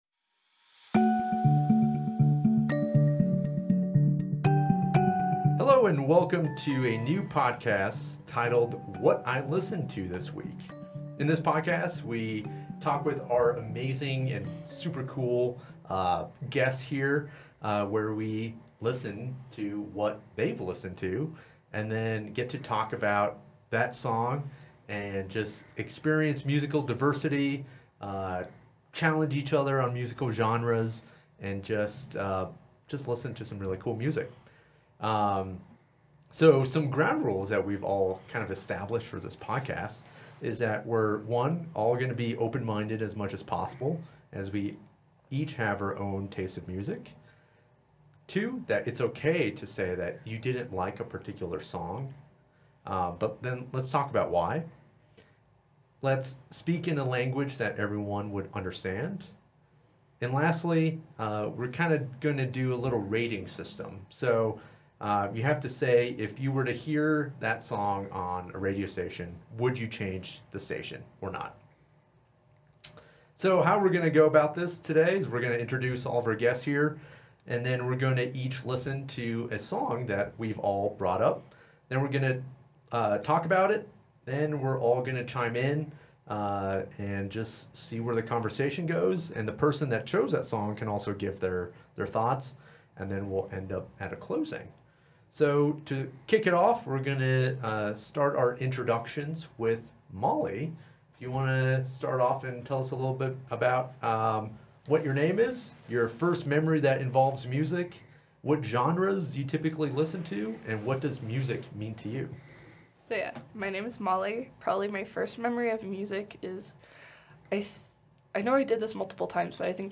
What I listened to this week is a new show about what our panelists listened to that week. It's a show about music discovery, appreciation, and discussion.